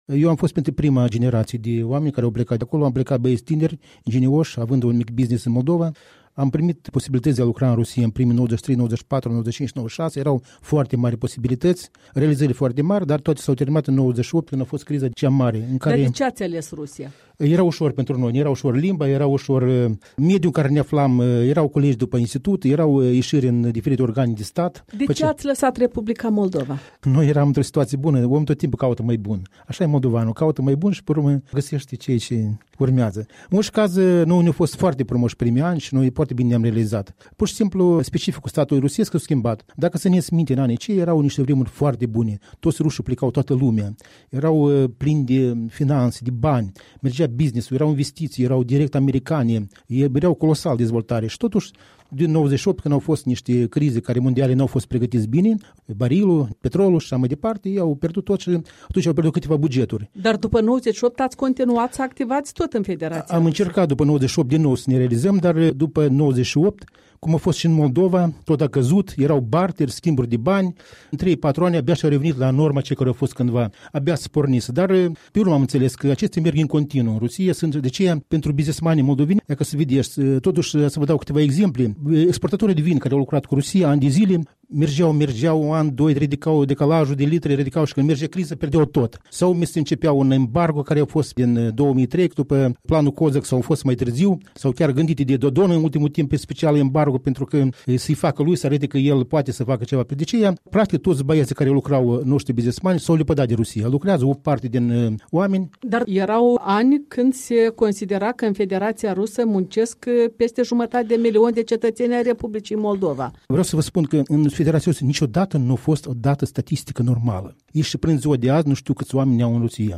Mărturii despre experiența de emigrant și modul în care sunt trăite așteptările și speranțele legate de posibilitatea unor schimbări pe care le poate aduce scrutinul parlamentar apropiat. Continuăm seria de interviuri cu reprezentanți ai diasporei.